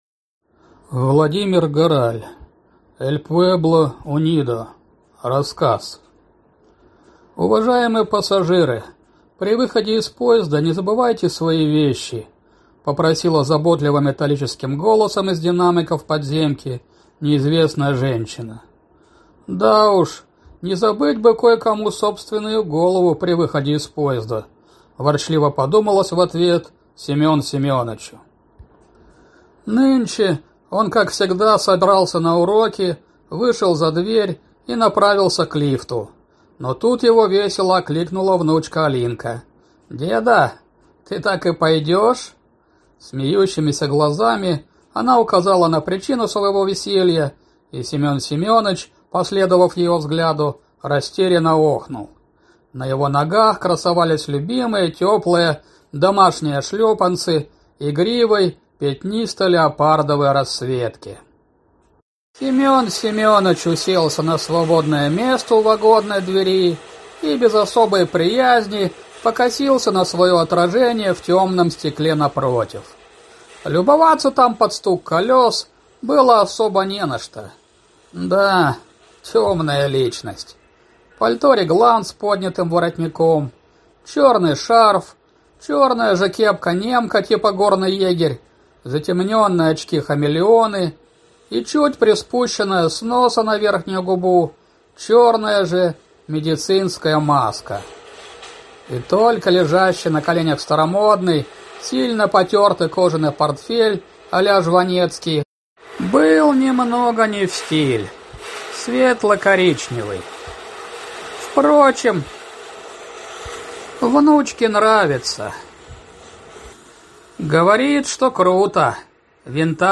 Аудиокнига Эль пуэбло унидо…